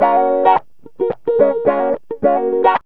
GTR 16A#M110.wav